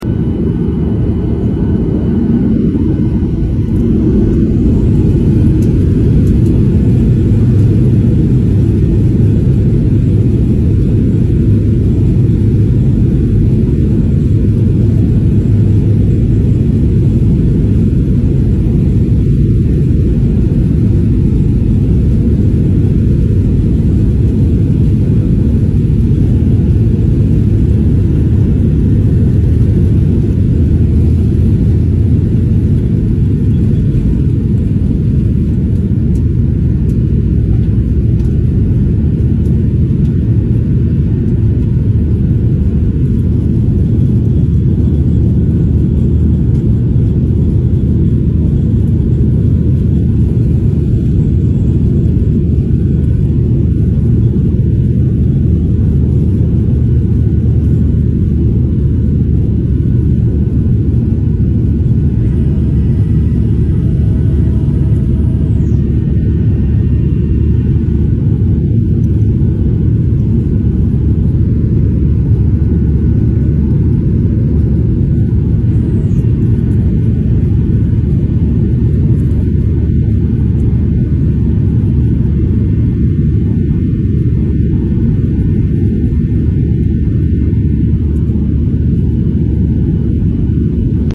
The Sound Of Jet Blast Sound Effects Free Download